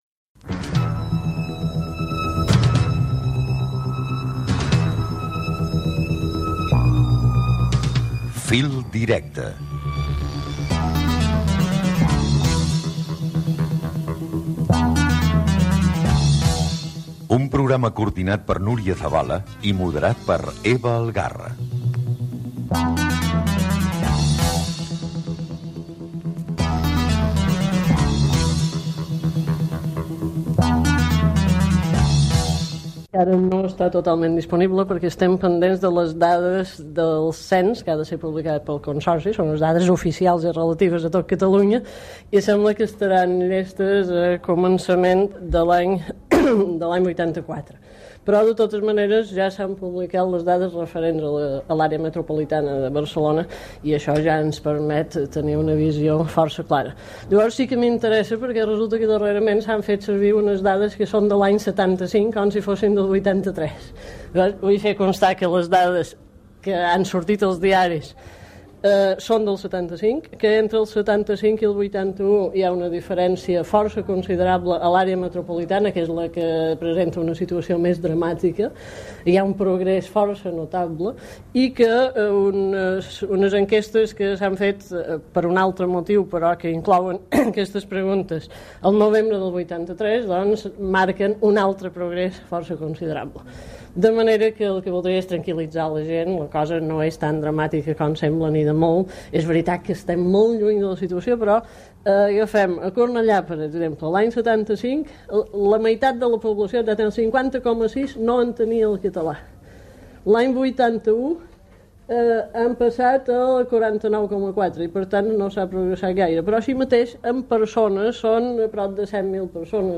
Aina Moll, Directora general de Política Lingüística de la Generalitat, parla sobre l'ús social del català i el debat de política lingüística fet al Parlament.